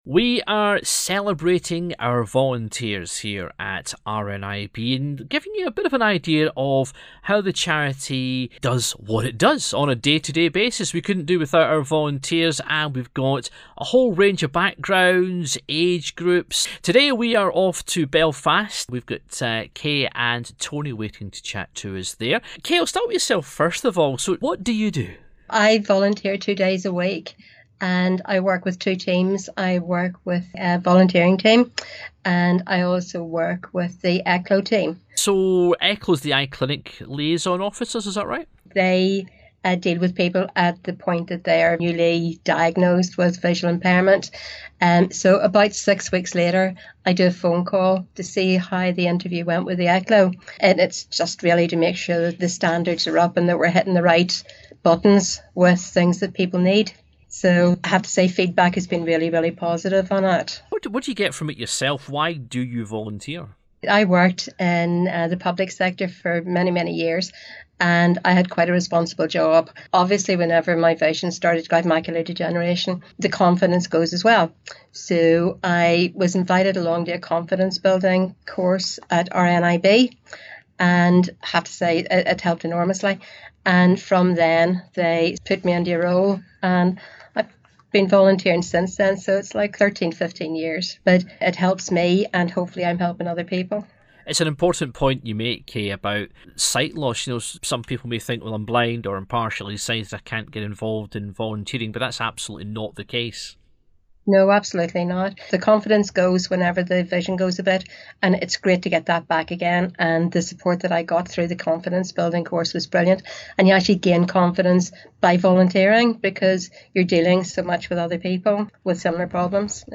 We are going to hear from some of the volunteers who give up their time for RNIB, what they do, why they do it and why they would recommend supporting the UK sight loss charity.